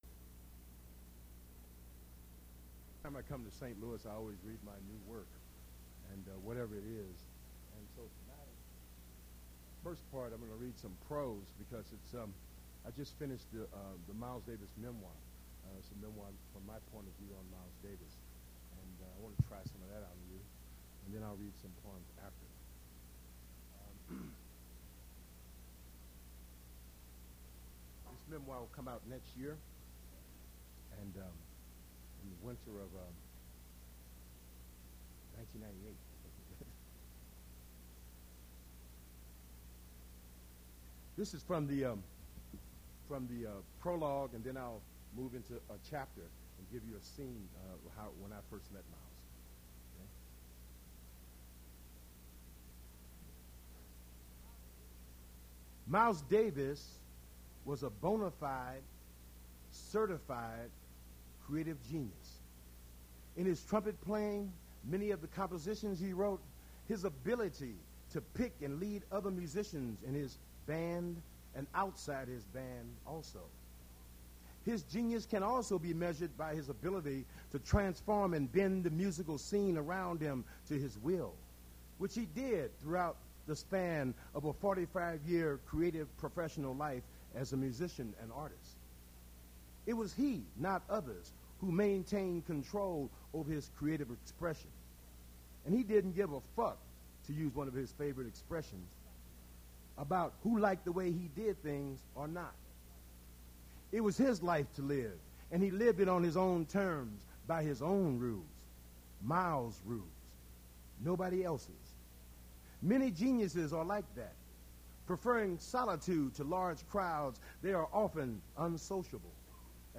Poetry reading featuring Quincy Troupe
Attributes Attribute Name Values Description Quincy Troupe poetry reading at Duff's Restaurant.
mp3 edited access file was created from unedited access file which was sourced from preservation WAV file that was generated from original audio cassette.